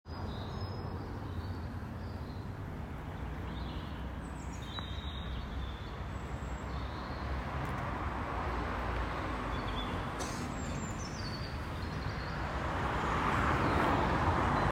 6. Birds at dusk and roaming vehicles
Cambridge-Park-4.m4a